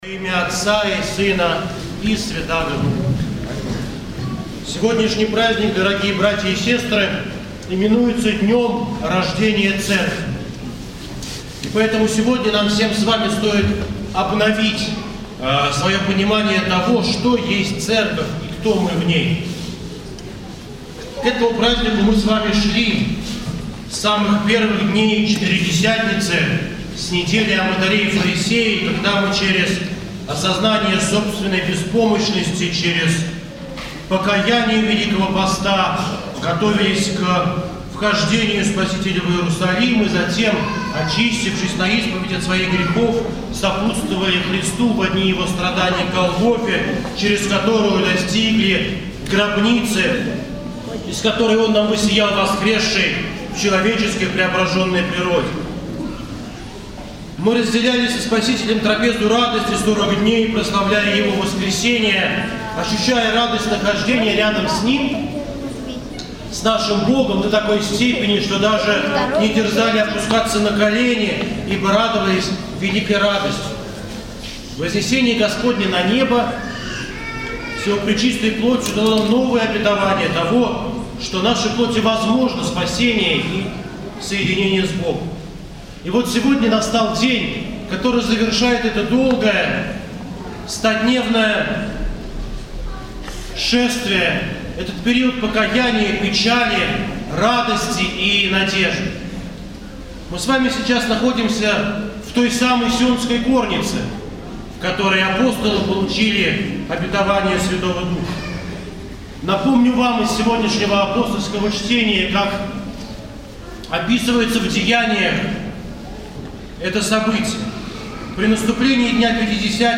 Слово настоятеля в День Святой Троицы
поздняя Литургия